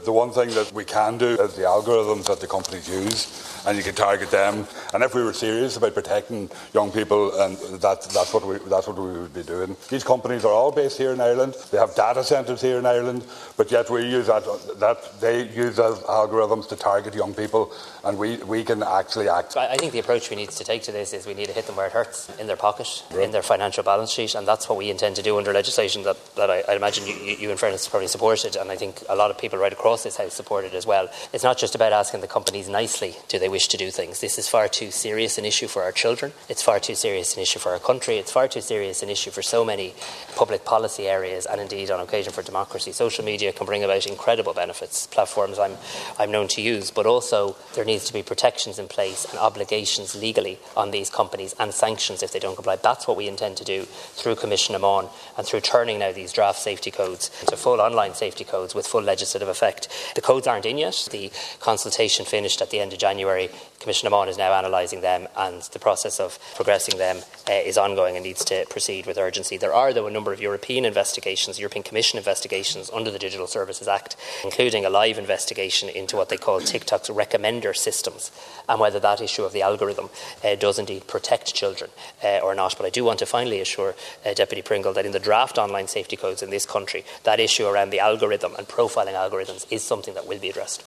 Deputy Pringle told the Dail we must get serious about protecting children…………..